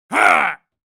Angry-man-growling-sound-effect.mp3